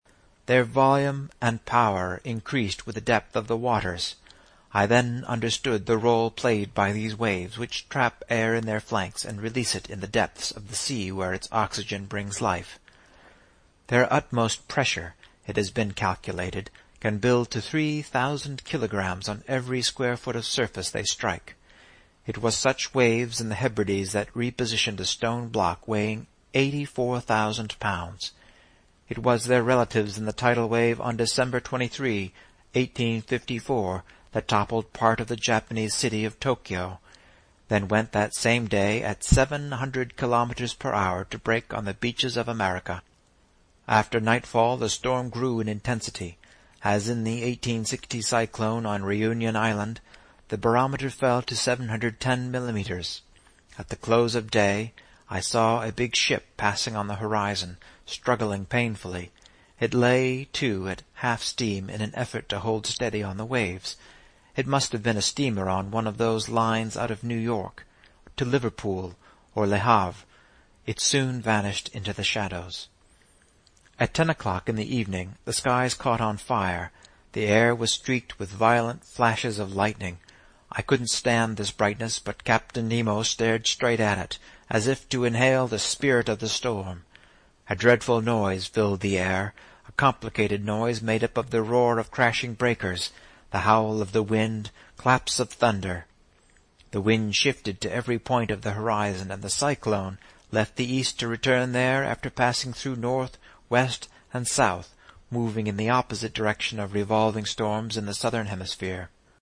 在线英语听力室英语听书《海底两万里》第524期 第32章 海湾暖流(15)的听力文件下载,《海底两万里》中英双语有声读物附MP3下载